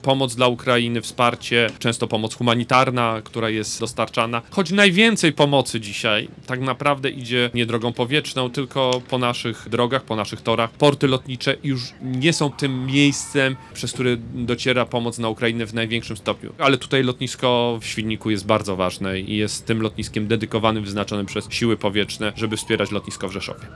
Wicepremier, minister obrony narodowej Władysław Kosiniak-Kamysz tłumaczy, że to efekt remontu rzeszowskiego lotniska i potrzeby pomocy naszemu wschodniemu sąsiadowi.